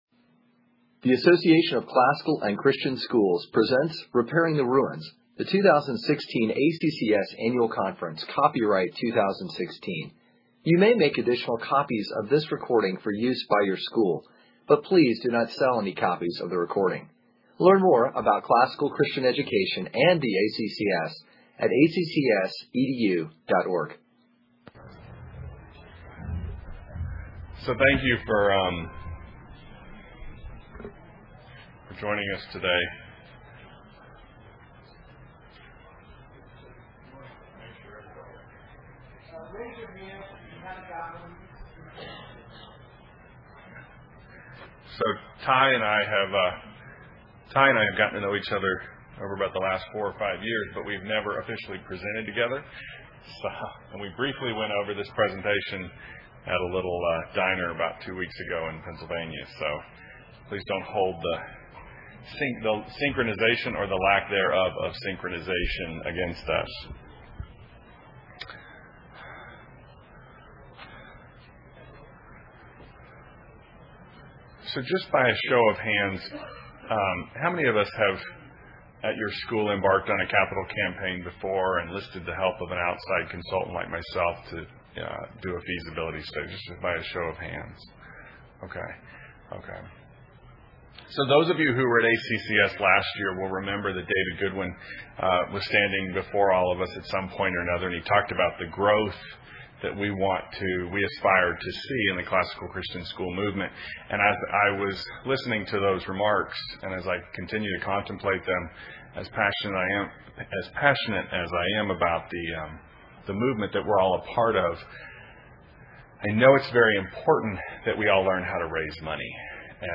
2016 Leaders Day Talk | 53:19:00 | Budgets & Finance, Fundraising & Development